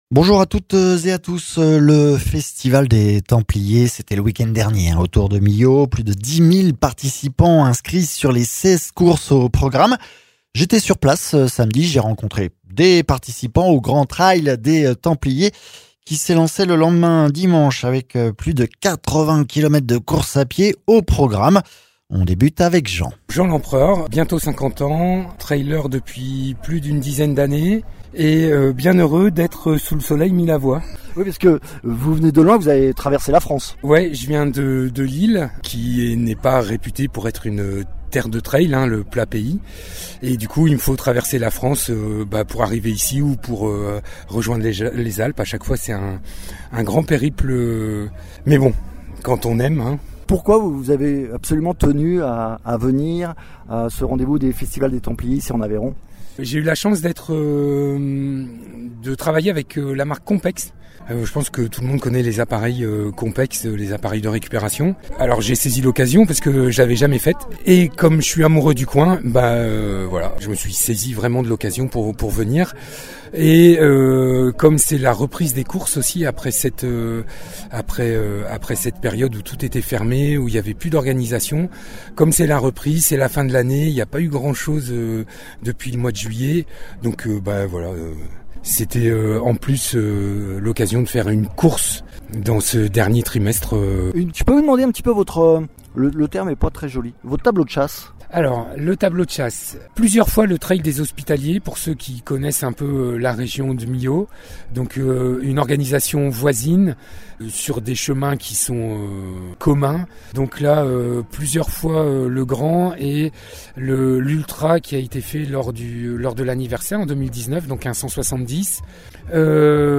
Plus de 10 000 personnes étaient inscrites aux 16 courses du week end de trail à Millau, rencontre avec quelques uns des participants au grand trail des templiers du dimanche.